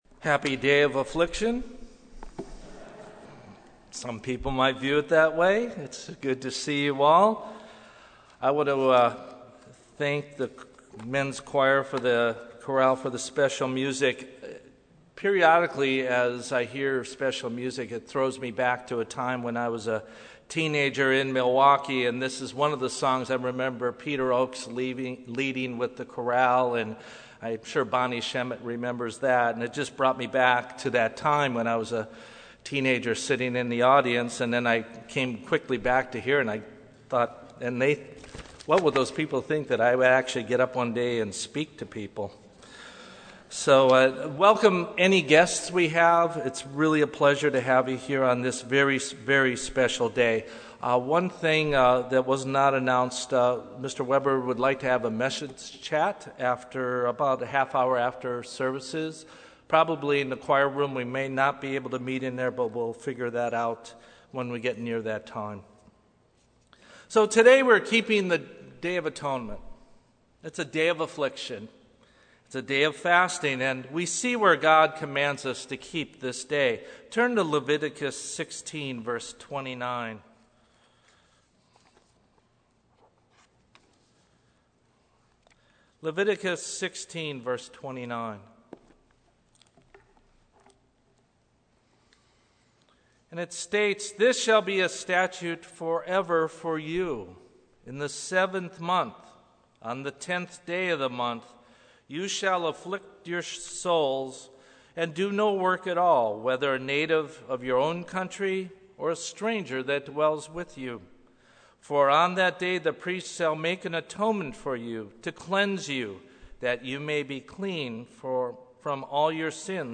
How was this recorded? Given in Los Angeles, CA